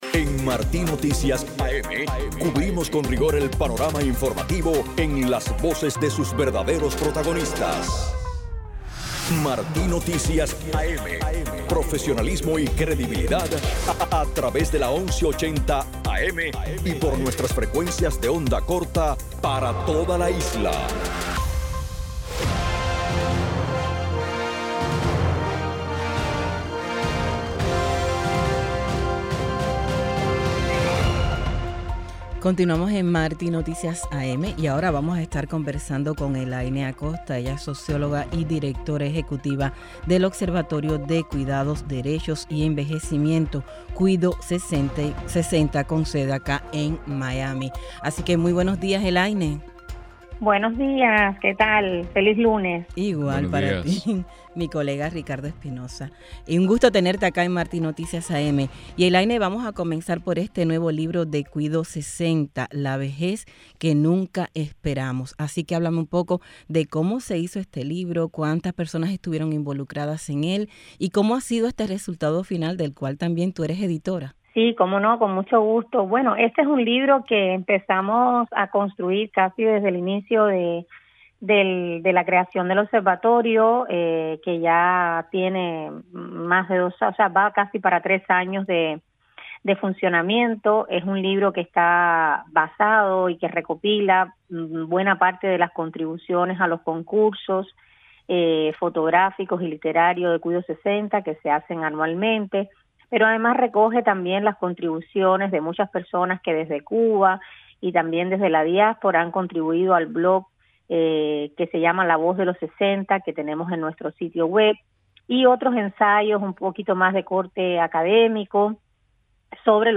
Entrevista en la revista informativa Martí Noticias AM